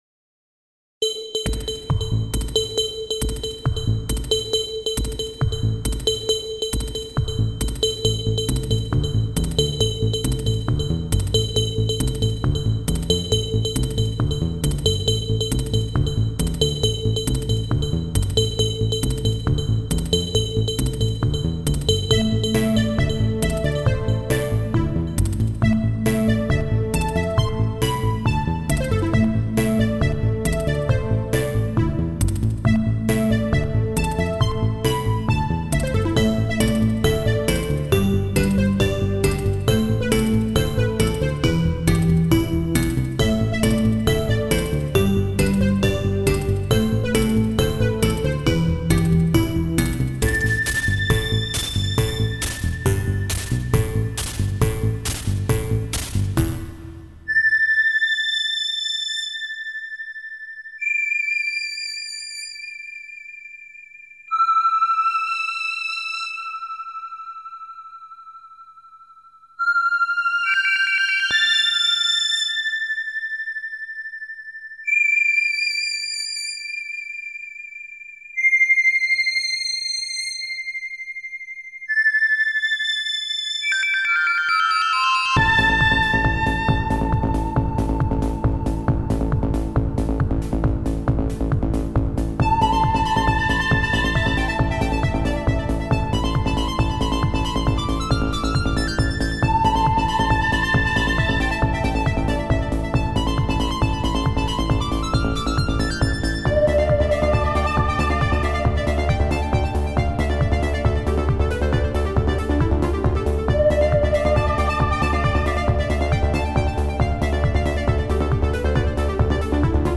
marvellous rare OPL/FM-bellwhistling